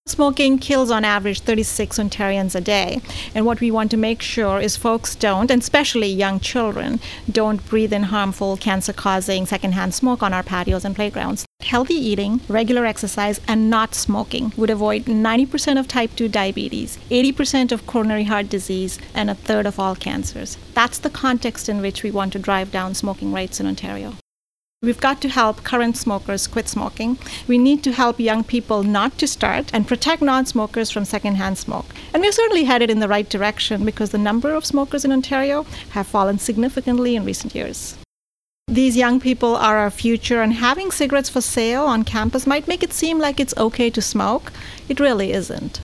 Recording Location: Toronto
Featured Speakers/Guests: Health Minister Dipika Damerla
Type: News Reports
0kbps Mono